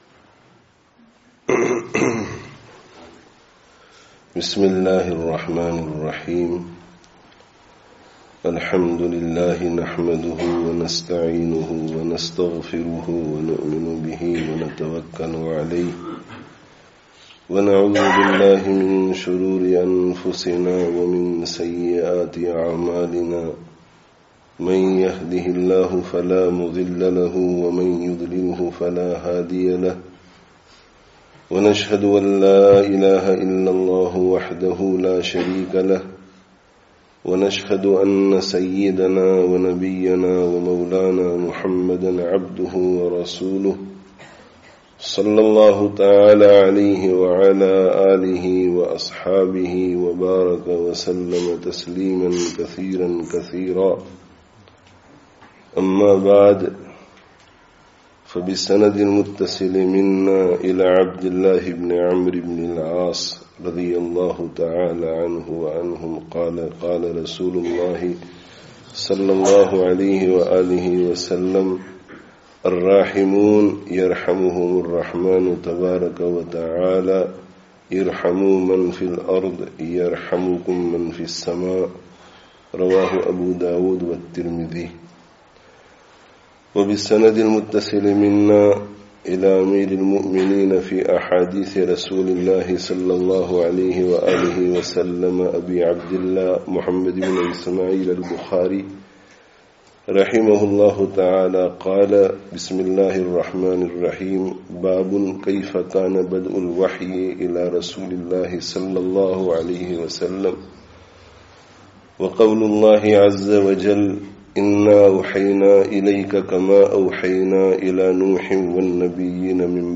Commencement of Sahīh-ul-Bukhārī (Masjid Nurul Islam, Blackburn 08/09/18)